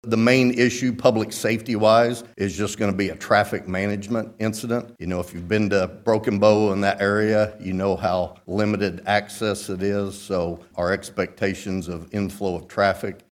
CLICK HERE to listen to commentary from Tim Tipton.
The commissioner of public safety, Tim Tipton, says the biggest concern will be the amount of traffic in southeast Oklahoma.